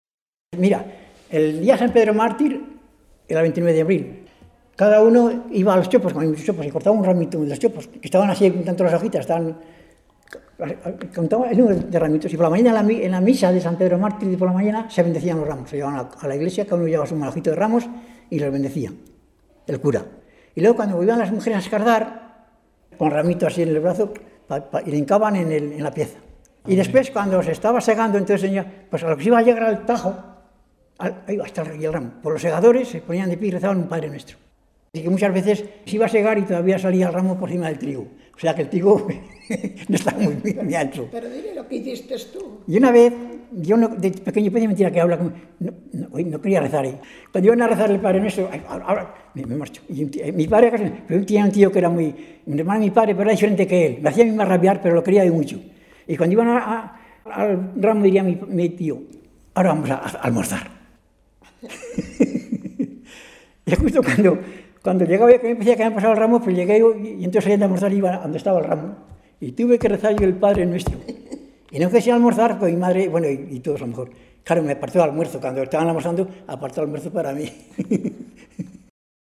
Clasificación: Religiosidad popular
Lugar y fecha de recogida: Logroño, 14 de marzo de 2003